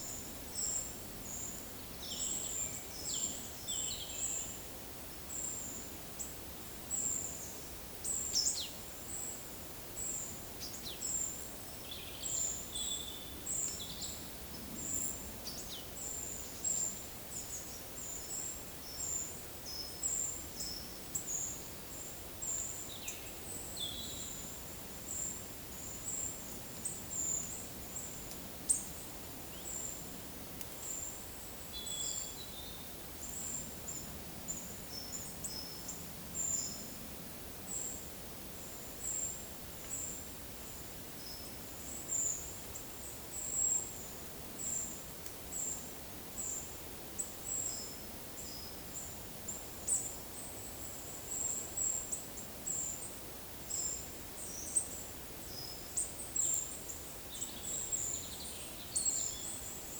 Certhia brachydactyla
Certhia familiaris
Sitta europaea
Regulus ignicapilla
Poecile palustris